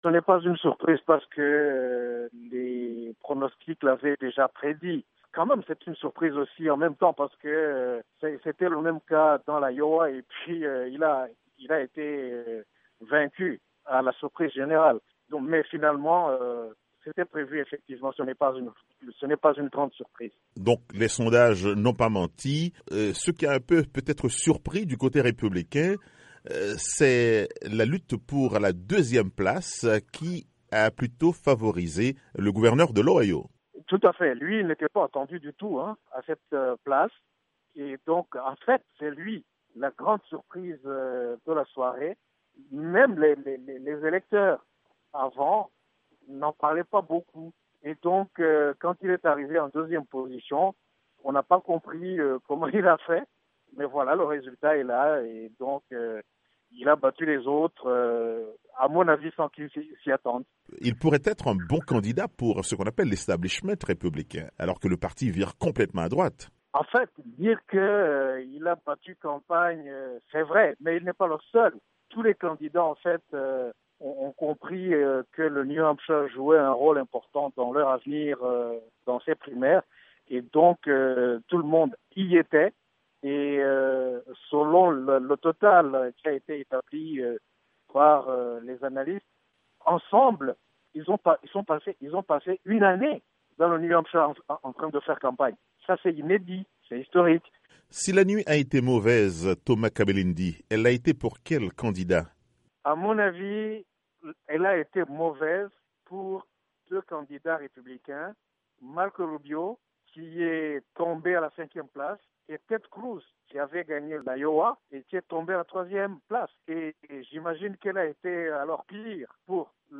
L'analyse